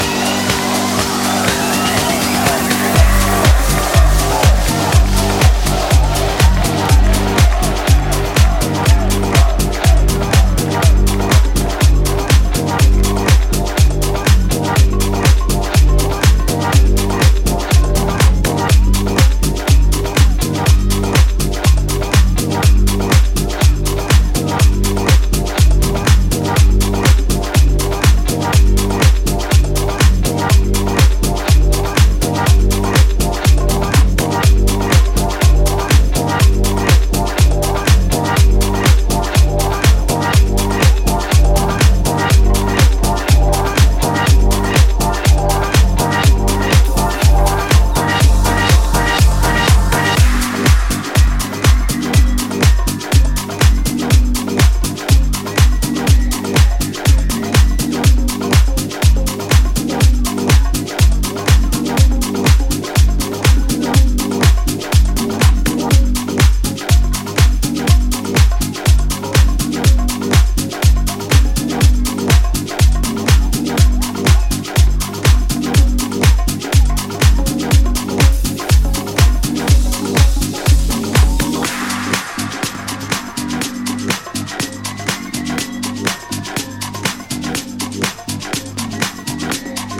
modern day house classic